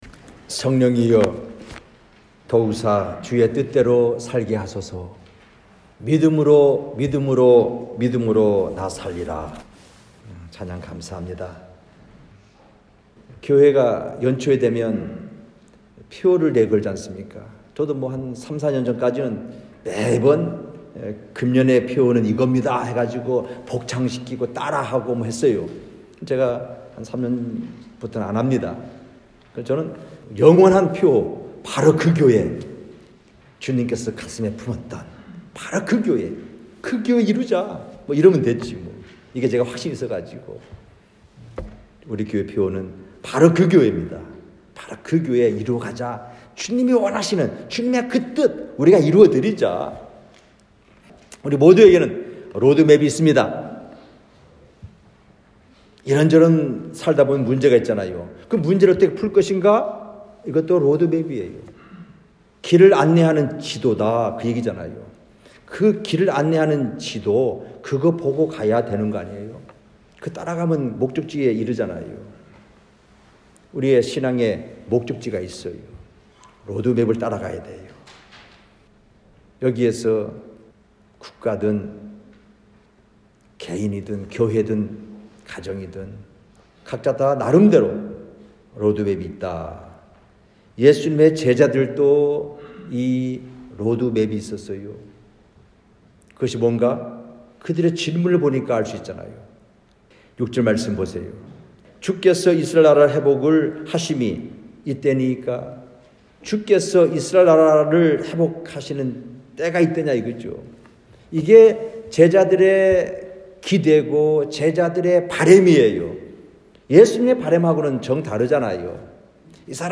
성경말씀 : 사도행전 1장 6-8절 설교자